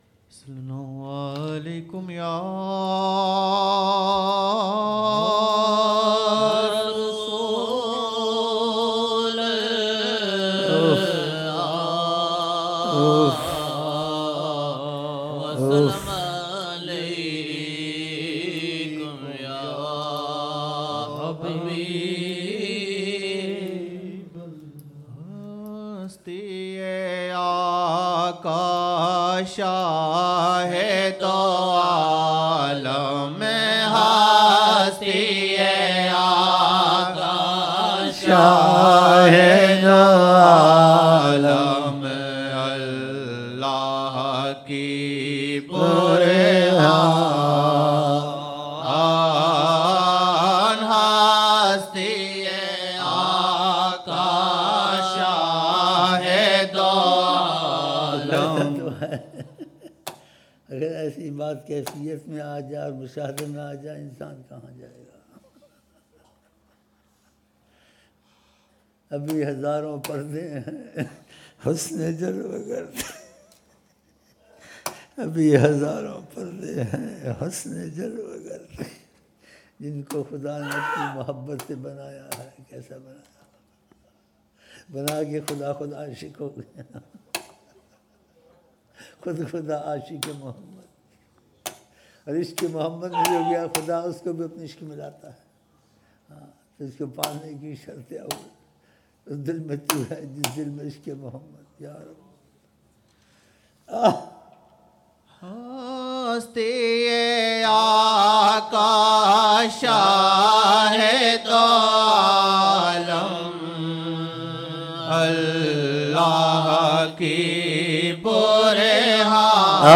Naat( Hasti Aaqa (SAW) shahy do alam Allah ki burhan ) 2007-01-01 01 Jan 2007 Old Naat Shareef